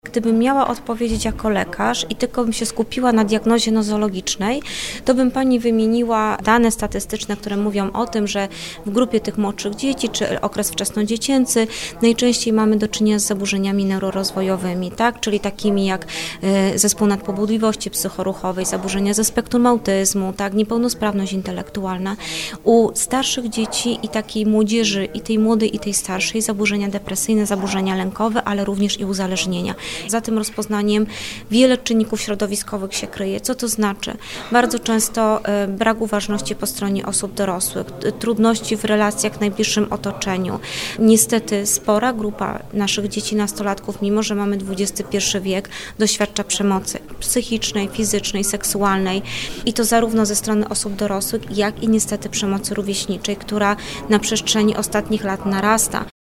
Dr n. med. Aleksandra Lewandowska – Konsultant Krajowa w dziedzinie psychiatrii dziecięcej – wyjaśnia, jakie zaburzenia wśród najmłodszych diagnozuje się najczęściej.